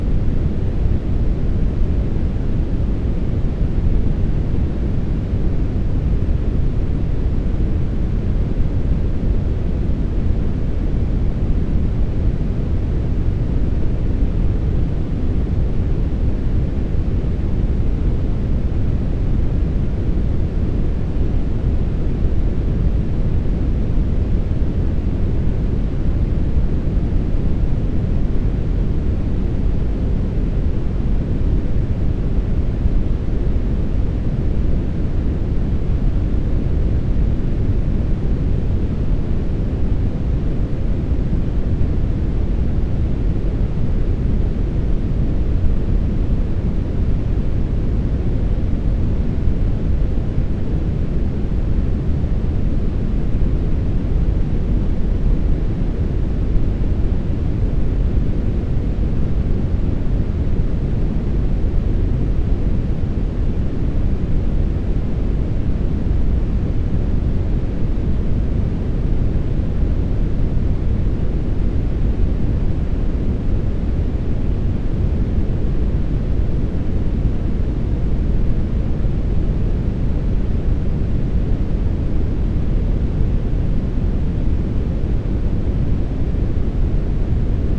Глубокие низкие частоты создают успокаивающий эффект, помогая отвлечься от стресса.
Коричневый шум для релаксации